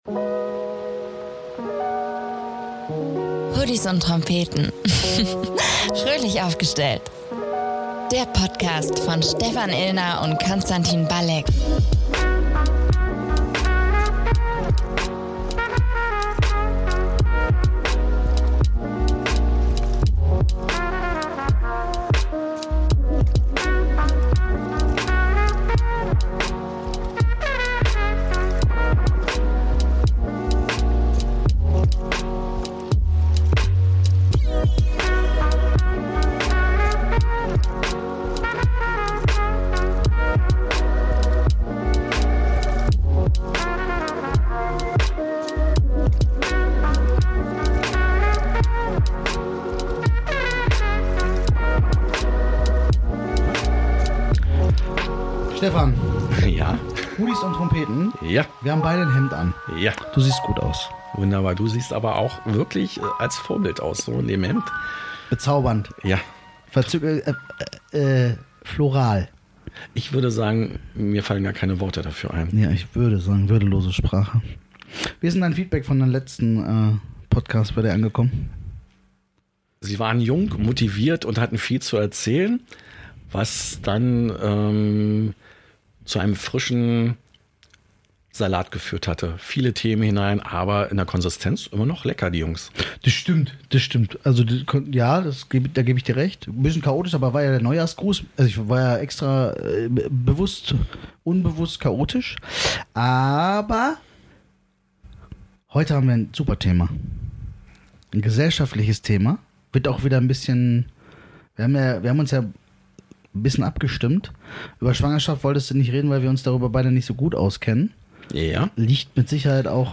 Mach dich bereit für einen Deep Talk, der zeigt: Mehr Gönnen, weniger Vergleichen – und aus negativen Emotionen positive Impulse zu schöpfen.